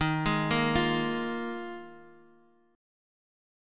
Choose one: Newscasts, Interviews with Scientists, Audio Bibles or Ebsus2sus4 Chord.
Ebsus2sus4 Chord